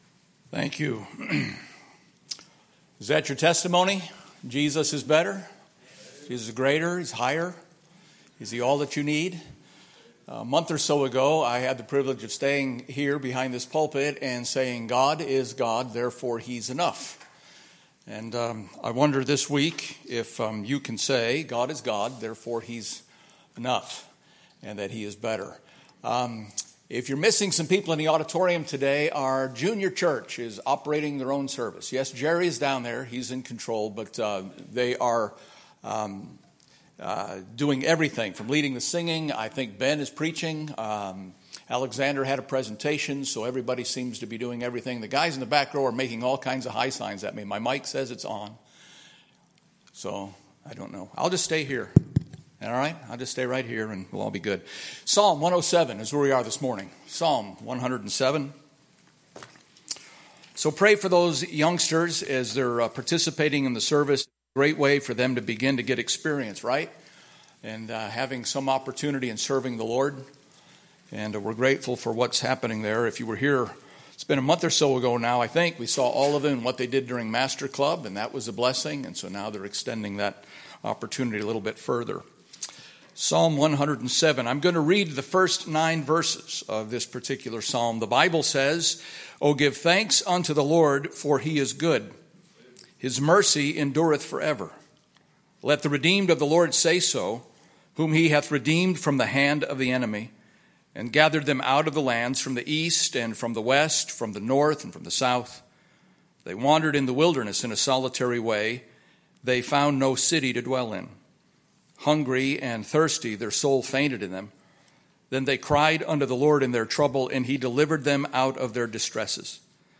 Due to technical issues, no video is available for this service, but you can listen to the audio of the message by clicking the icon below.